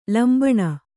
♪ lambaṇa